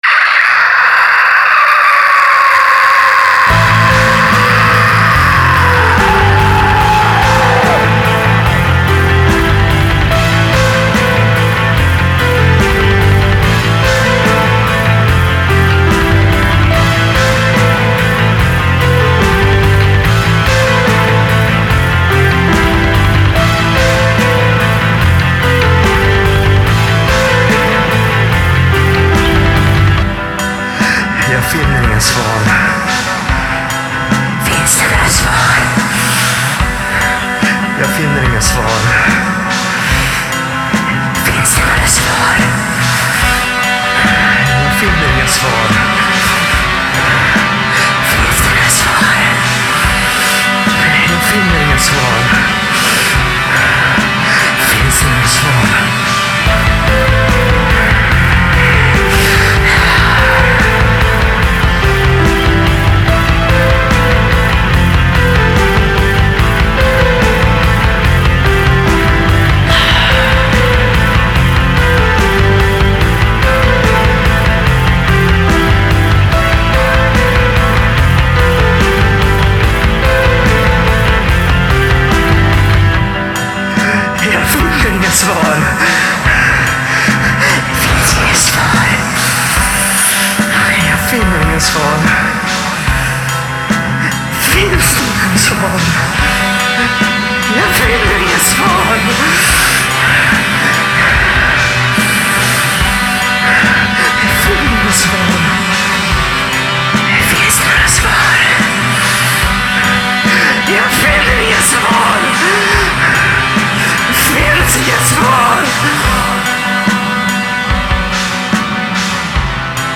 *фоновая композиция - Lifelover "Mental Central Dialog"
Рубрика: Поезія, Філософська лірика